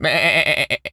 goat_baa_calm_02.wav